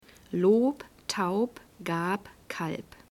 The German flag  Sounds 2: 'b' in medial position